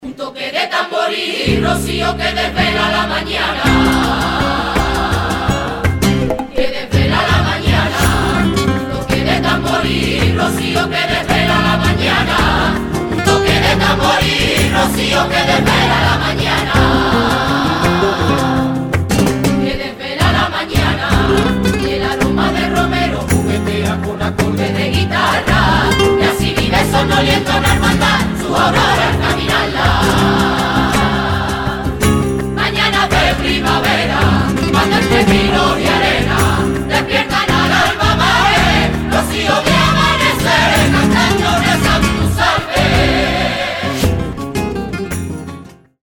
con temas rocieros y villancicos
Gaita rociera
Guitarras
Bajo
Percusión